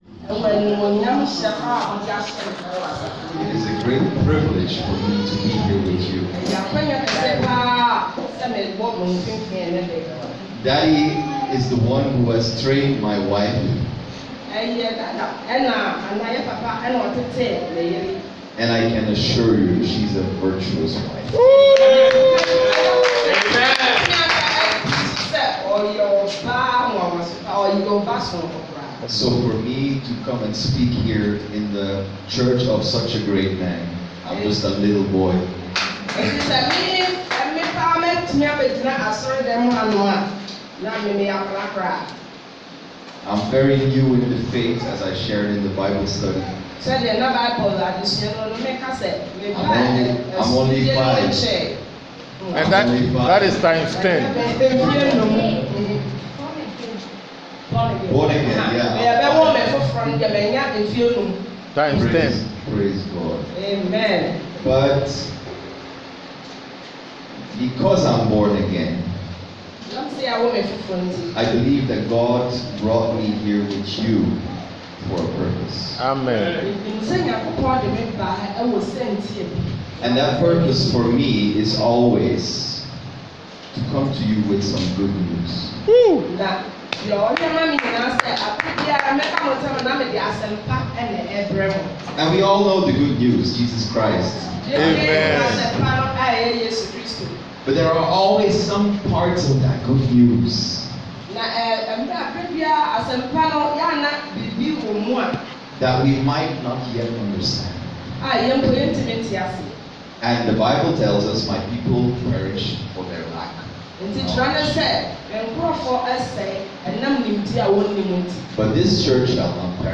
Our Sermons are public domain and may be read listened to copied played without any restrictions.